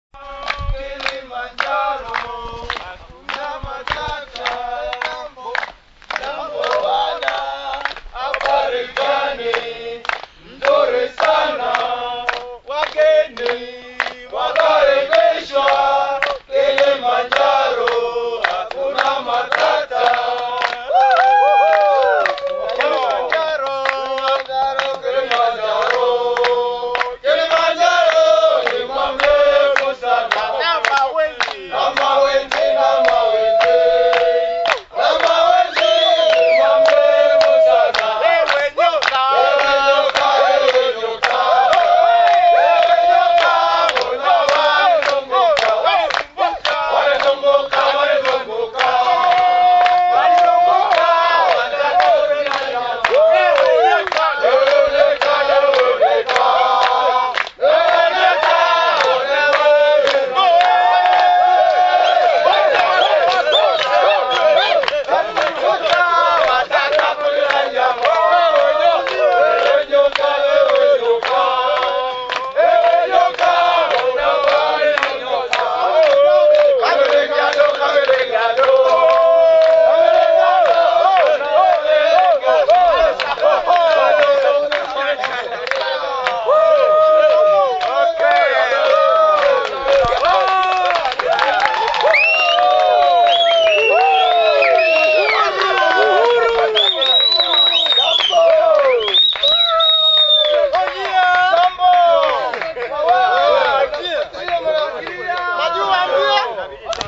The trail gets steeper, and another two hours elapse before we reach the blessed Mweka Camp at 11,000 feet.
A standard part of the last-morning routine is the singing of the Kilimanjaro song by the porters.
partial recording (1.7MB MP3) of another group's porters performing this (I will miss our own).
kilimanjaro_song.mp3